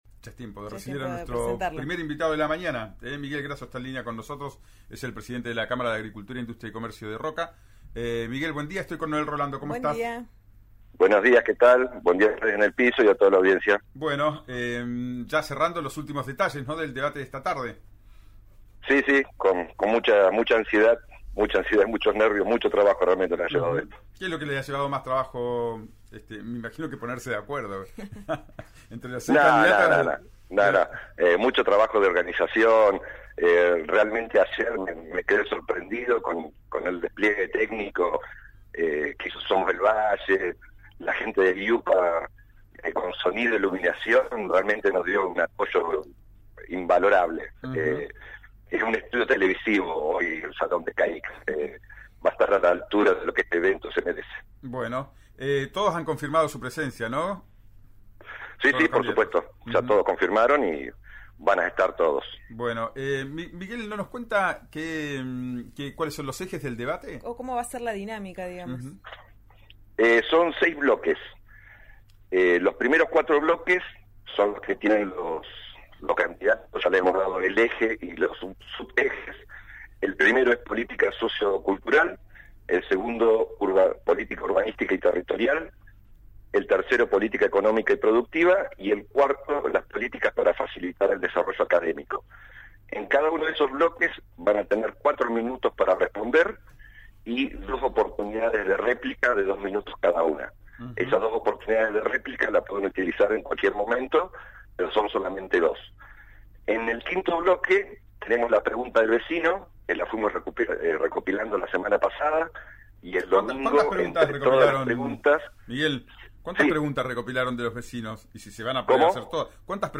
en diálogo con RÍO NEGRO RADIO que brinda los últimos detalles del encuentro que tendrá lugar en sus instalaciones